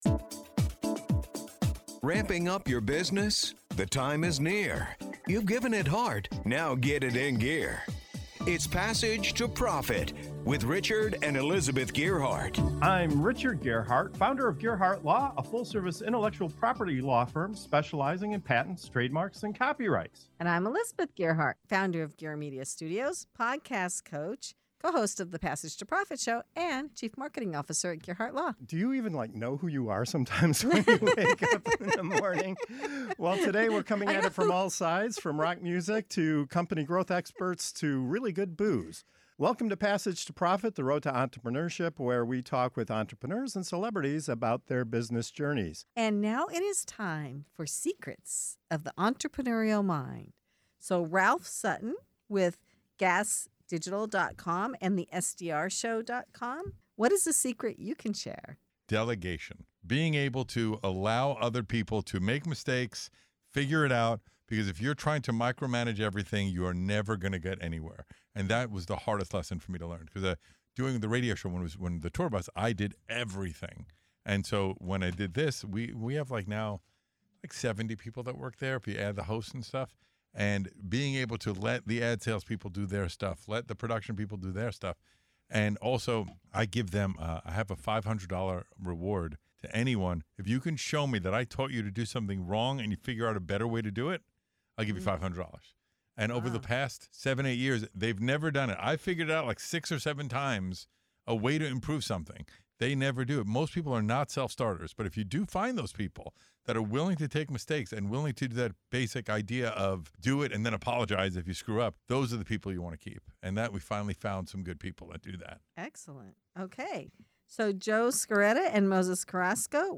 In this segment of "Secrets of the Entrepreneurial Mind" on Passage to Profit Show, top entrepreneurs reveal the hard-earned secrets that transformed their businesses and leadership styles. From learning to delegate without fear, to practicing active listening, embracing the right partners, and following through on every promise, these insights show what it really takes to scale, lead, and succeed.